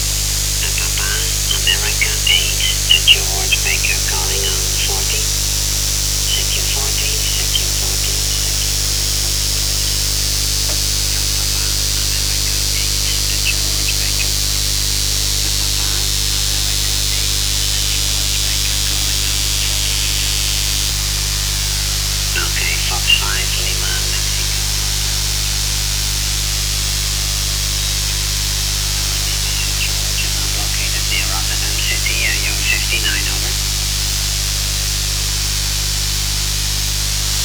Am Abend konnten mit einer Drahtantenne von etwa 10 Metern Länge viele Rundfunkstadionen im31, 41 und 49 Meter Band empfangen werden.
Küstenstation SVO in Griechenland auf 8,425 MH Amateur PA8? an F5L? im 40m Band in SSB CW im 40 Meter Band wegen der zu großen Bandbreite sind 2 Stationen hörbar RTTY unbekannte Betriebsart (FAX Abart?)